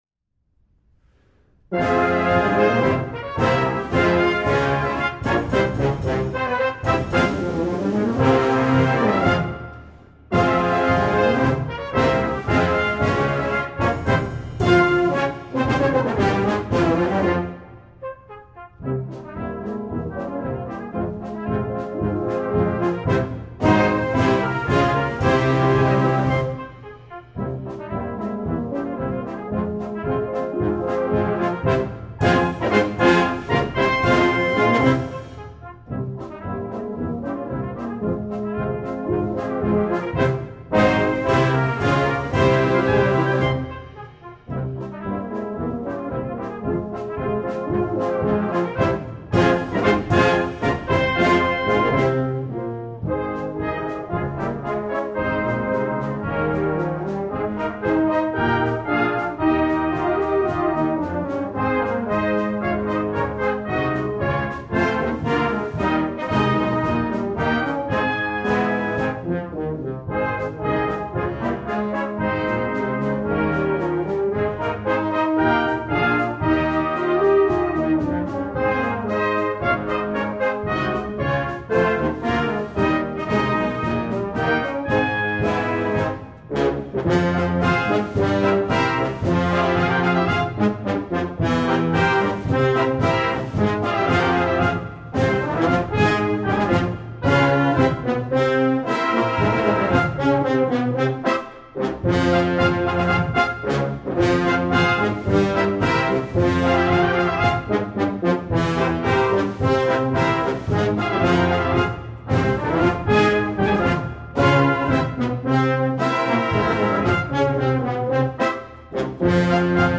Composition Style: March